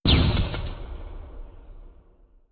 Gas Grenade Explosion.wav